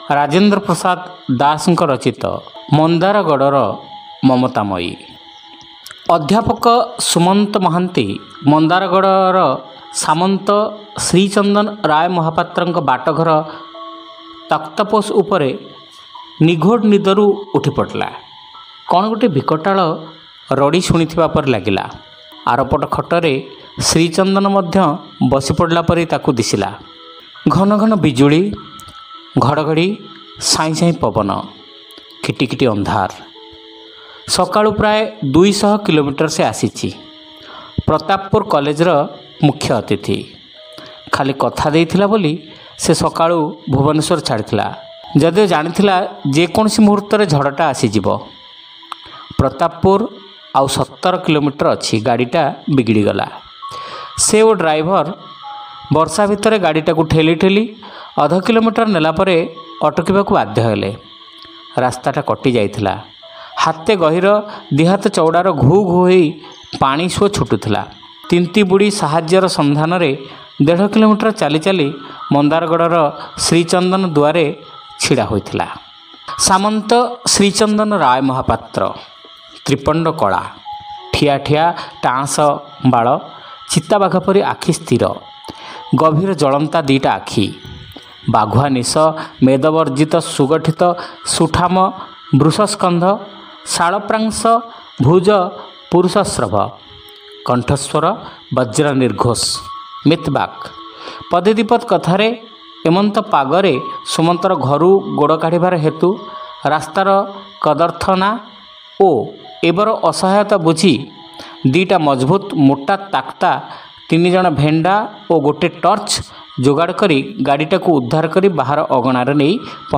ଶ୍ରାବ୍ୟ ଗଳ୍ପ : ମନ୍ଦାରଗଡ଼ର ମମତାମୟୀ